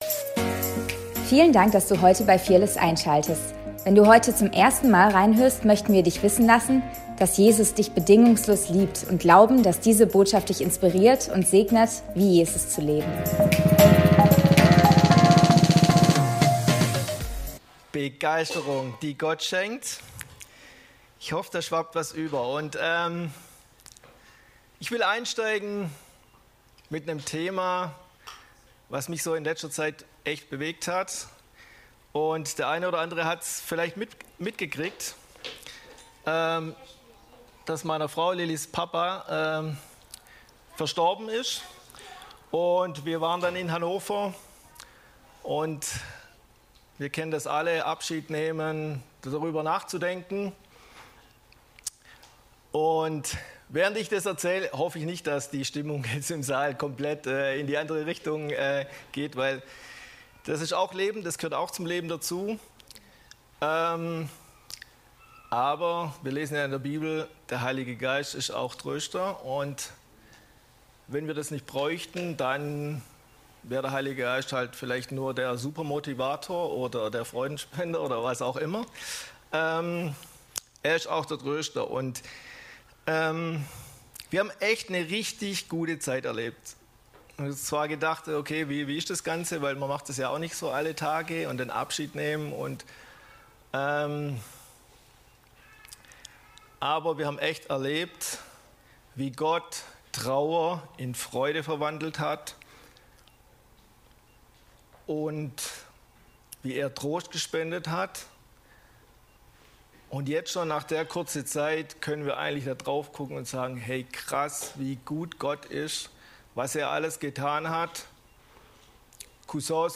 Predigt vom 03.08.2025